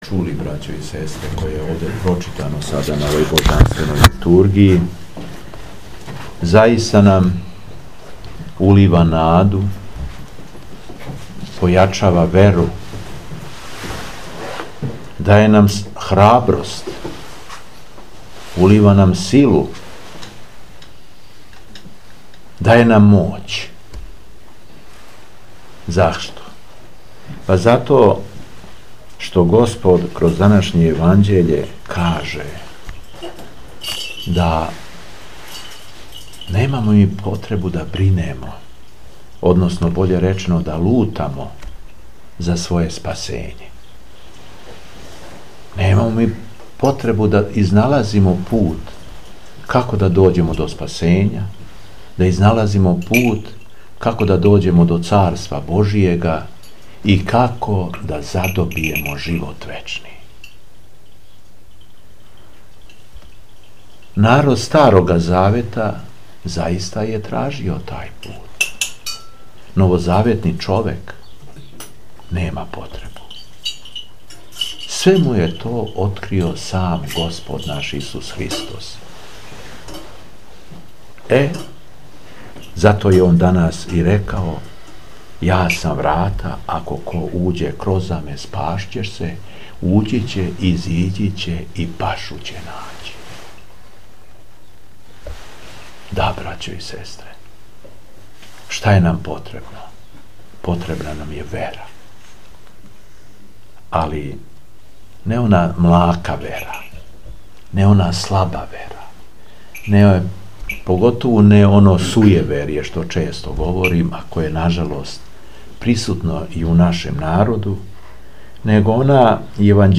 Беседа Епископа шумадијског Г. Јована
У среду, 17. јануара 2018. године, када Српска Православна Црква слави Светих 70 апостола и Светог Евстатија, архиепископа српског, Епископ шумадијски Господин Јован служио је Свету Архијерејску Литургију у манастиру Липар.